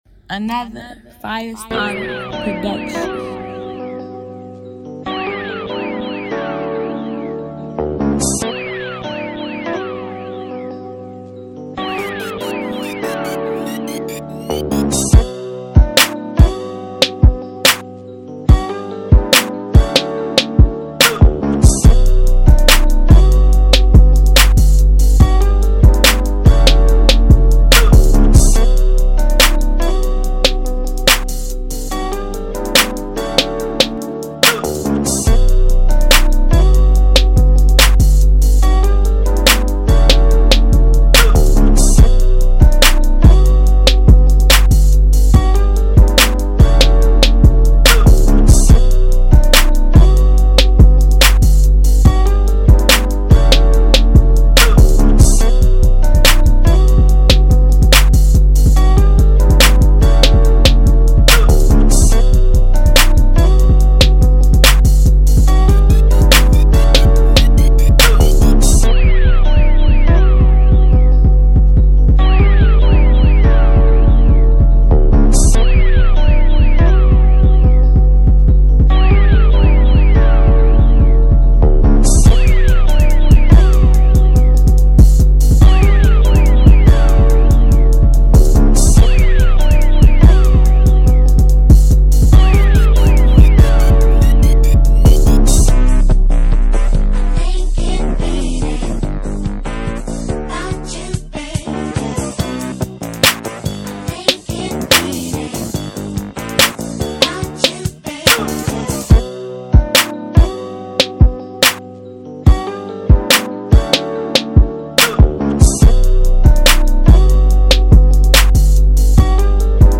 free instrumentals